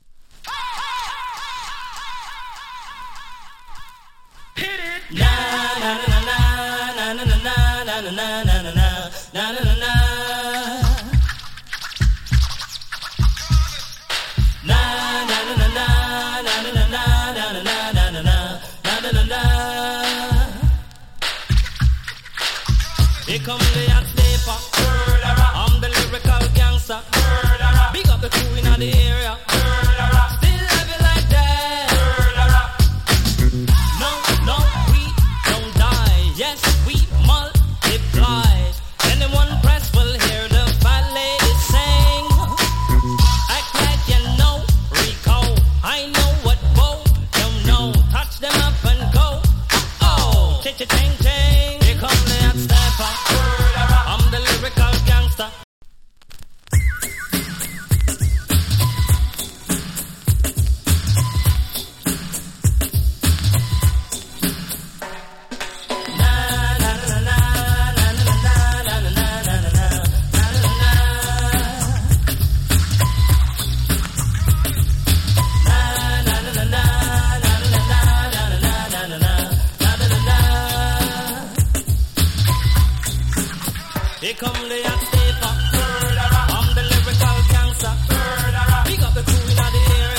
DANCE HALL 90'S
A：VG+ / B：VG+ ＊スリキズ少し有り。チリ、ジリ、パチノイズ少し有り。